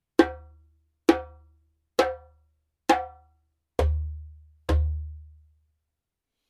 Djembe made in Mali at KANGABA workshop
キレよくラウドなレンケサウンド。